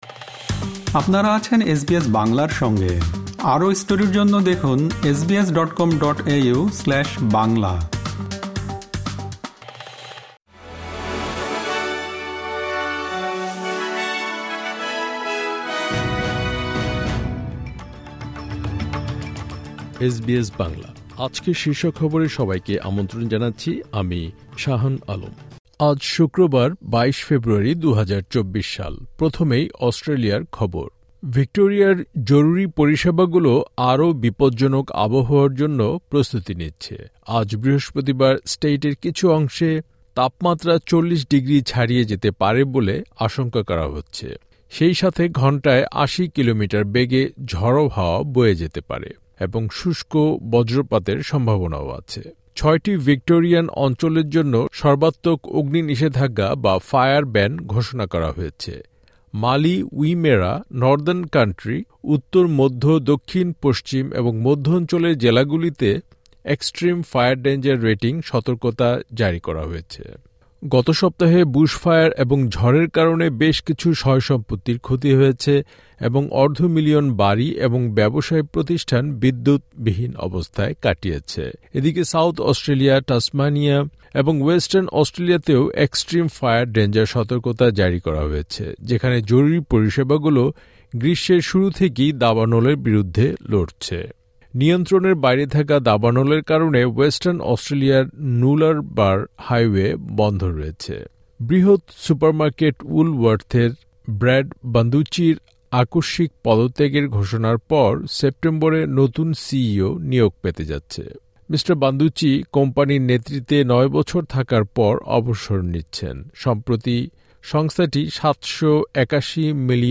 এসবিএস বাংলা শীর্ষ খবর: ২২ ফেব্রুয়ারি, ২০২৪